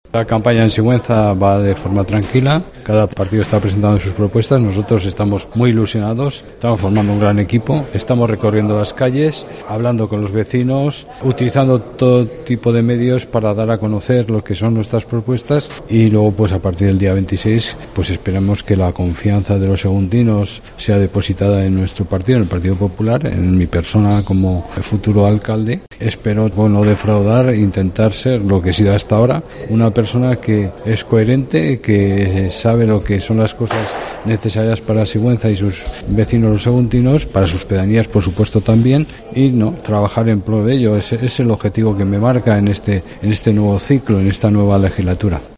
En Sigüenza, el candidato a renovar la Alcaldía, José Manuel Latre, ha señalado a los micrófonos de COPE que la campaña se está desarrollando con absoluta tranquilidad.